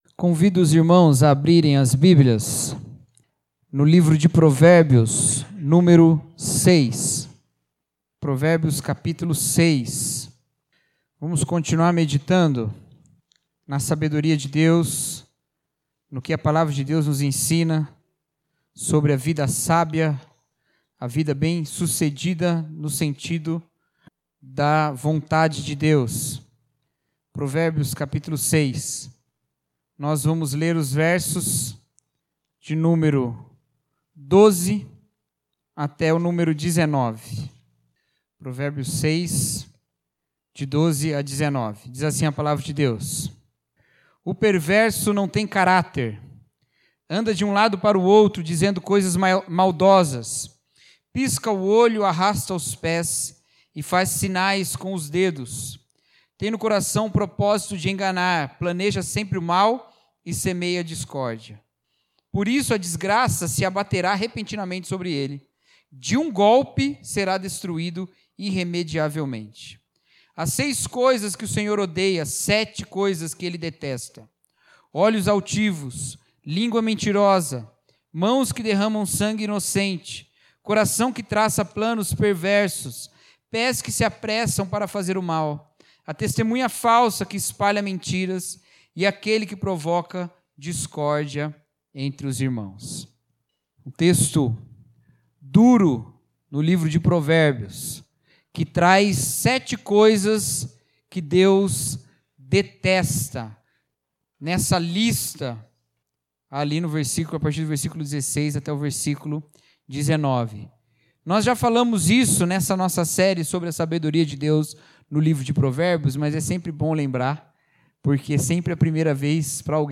Mensagem: A Identidade da Sabedoria